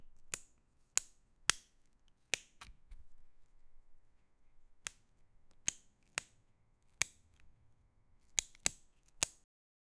Apple cutting
apple-cutting--ottk6ead.wav